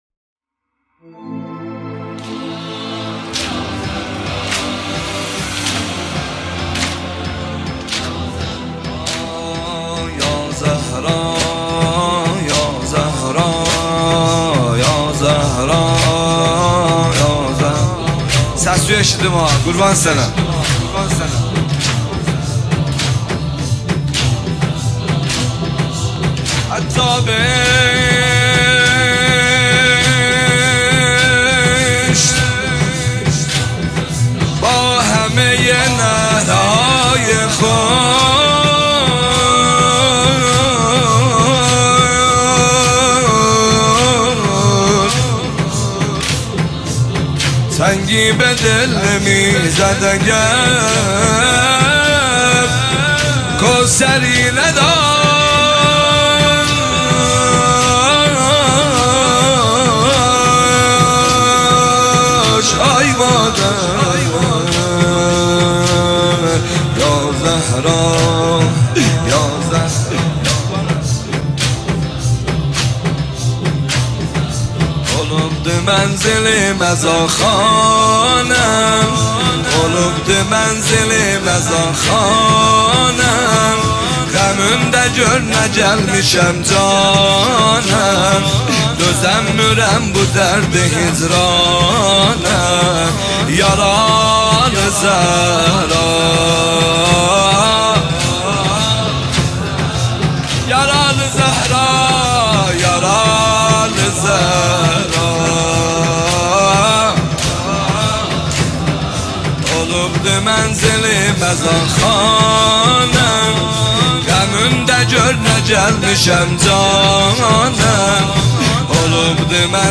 دانلود مداحی ترکی
با نوای دلنشین
ویژه ایام فاطمیه